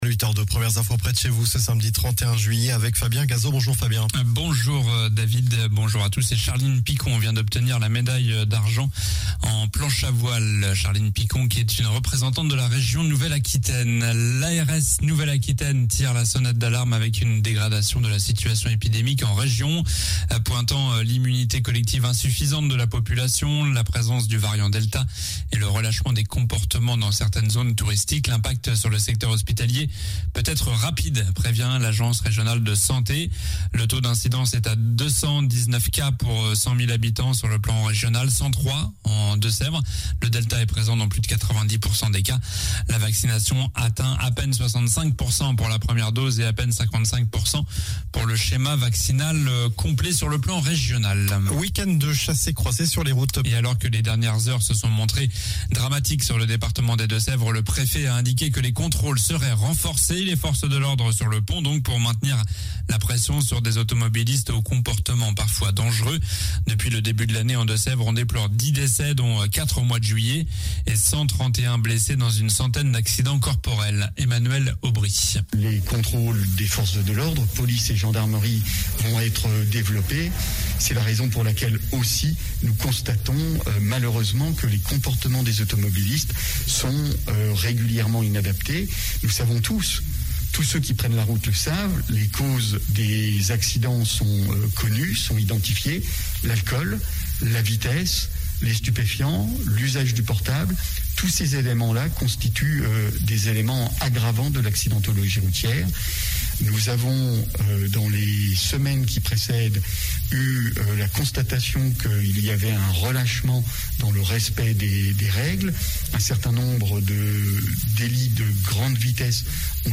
Journal du samedi 31 juillet (matin)